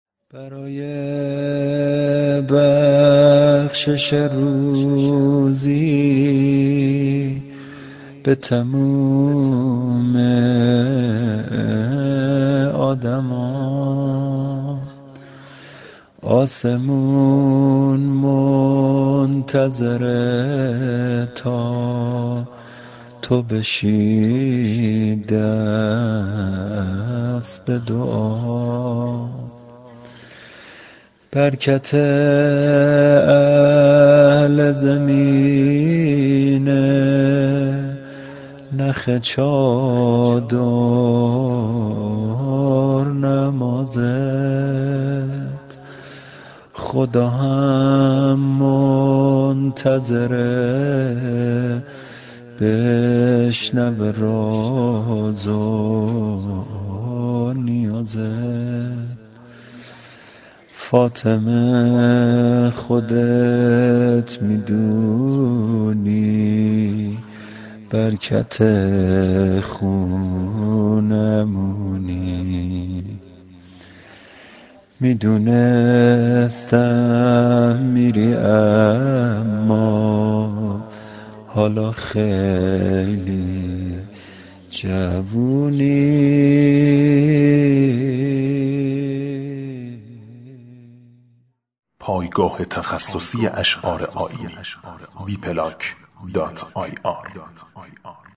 زمزمه